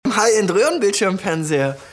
Roehrenbildschirmfernseher.mp3